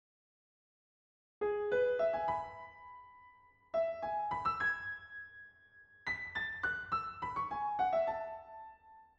passionate (violins):